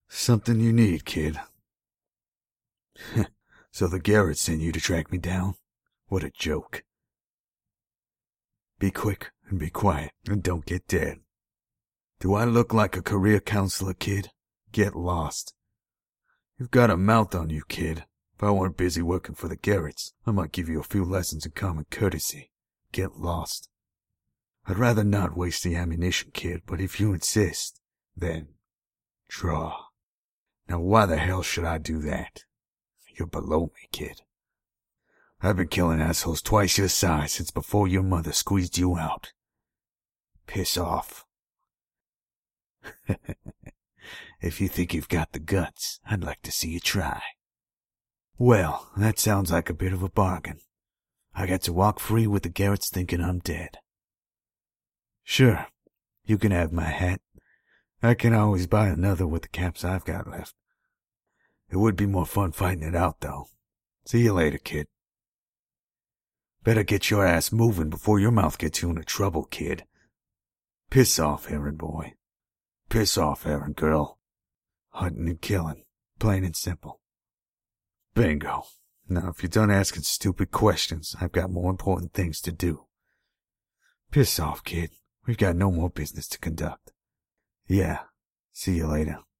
Southern accent preferred.
Tone: Baritone or Bass.
(this was the old actor's audio)
But today, there's a fair bit of sibilance and an unnatural quality to the lines, probably due to compression, the mic itself or the abuse of the Noise Reduction feature in Audacity.